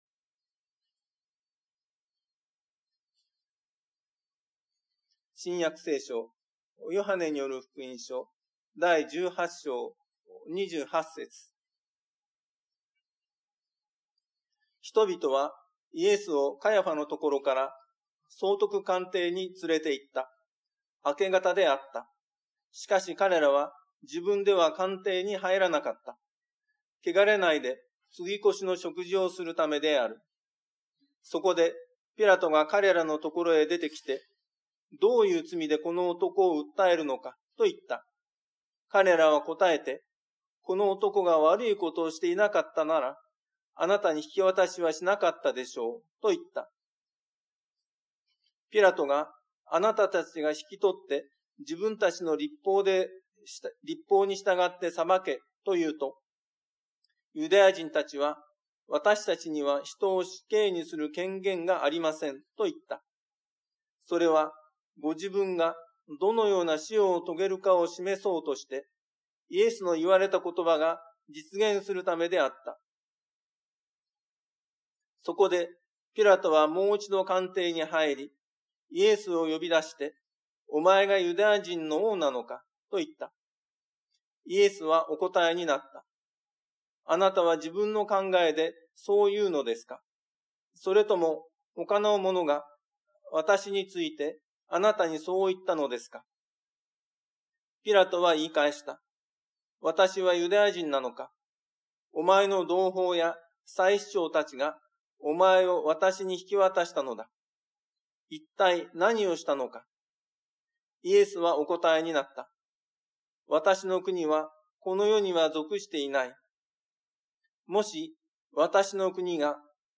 毎週日曜日に行われ礼拝説教のアーカイブデータです。
礼拝説教を録音した音声ファイルを公開しています。